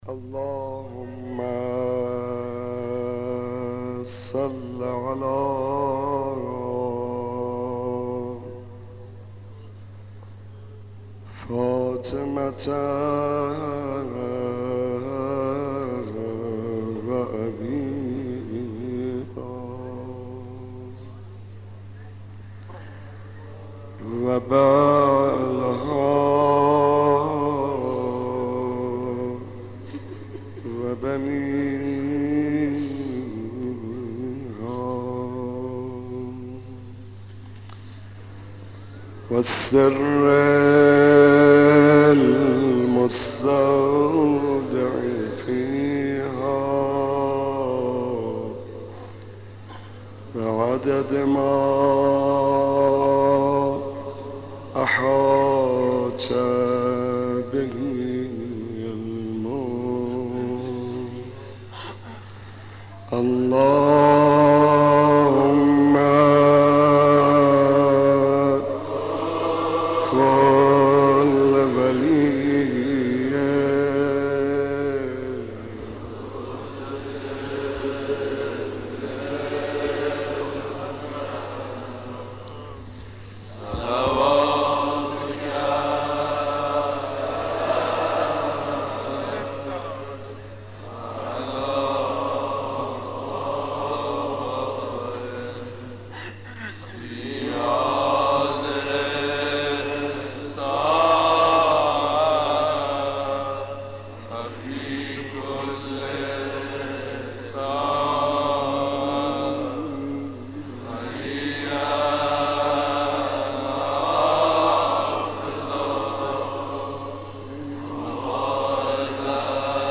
مداح
مناسبت : عاشورای حسینی